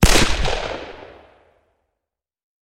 Звуки револьвера
Звук выстрела из револьвера Кольт